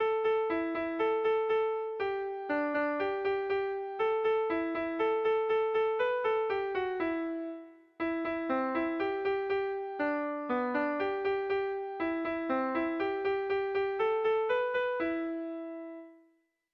Haurrentzakoa
Zortziko txikia (hg) / Lau puntuko txikia (ip)
ABD1D2